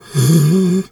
bear_roar_soft_06.wav